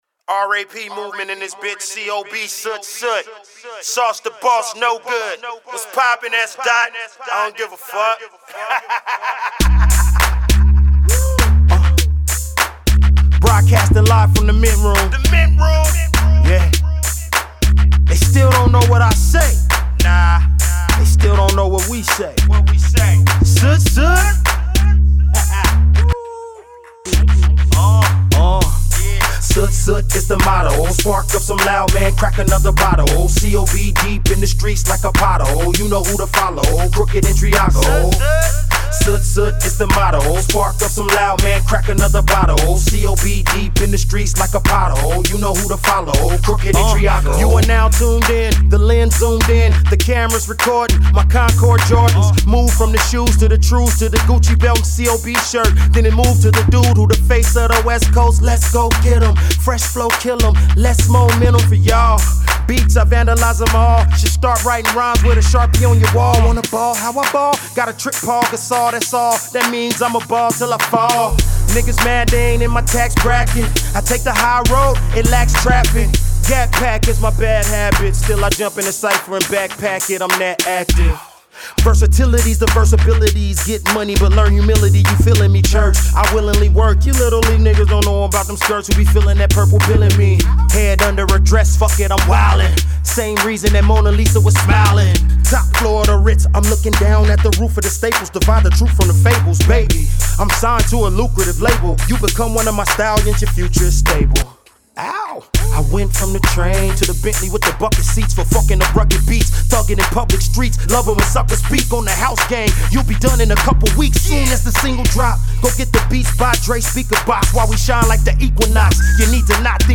Still on his freestyling spree